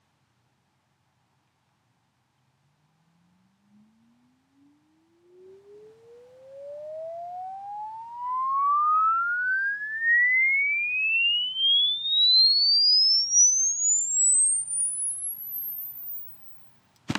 sine-sweep.m4a